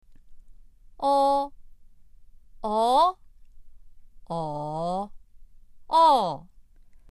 つぎに、それぞれの母音に声調（四声）のついた発音を確認してみましょう。
o1-4.mp3